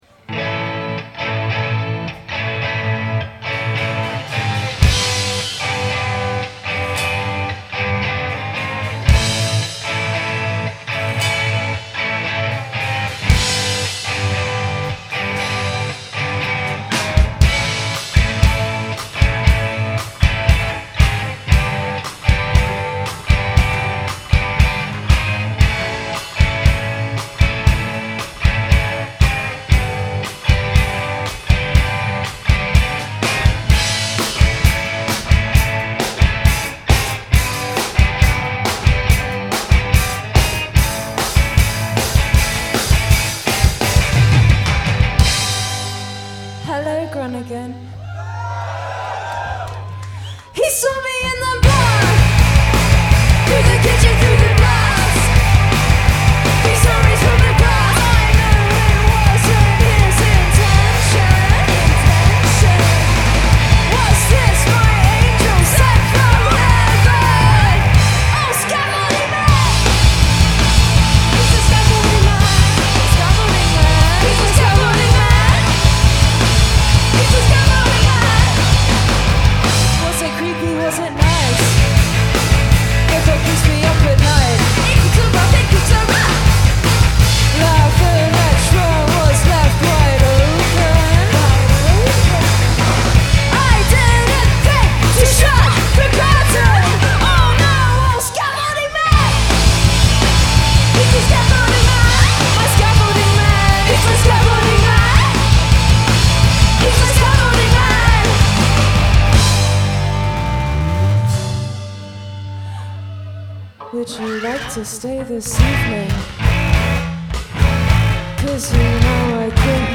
recorded at Eurosonic 2025